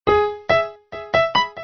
piano nē 71
piano71.mp3